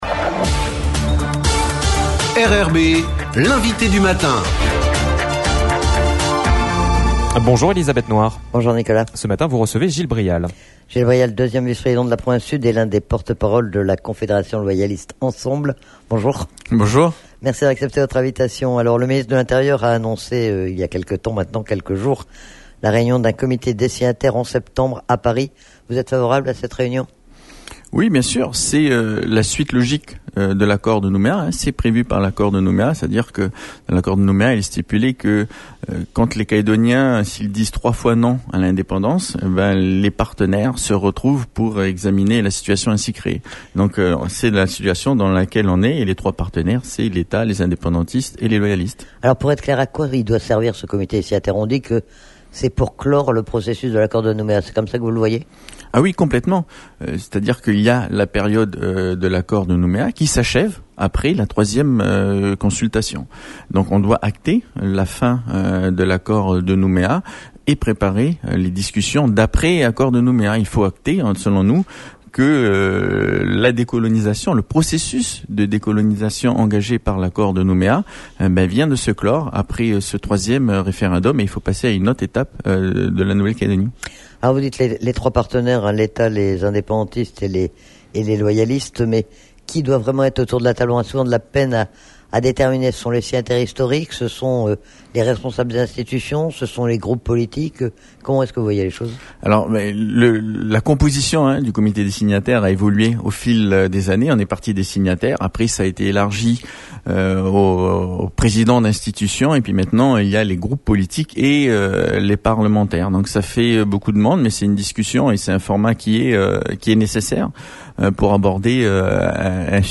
Gil Brial 2ème vice-président de la Province Sud et l'un des porte-paroles de la confédération loyaliste "Ensemble !" est interrogé sur l'actualité politique calédonienne et notamment l'annonce de la réunion, en septembre, du comité des signataires.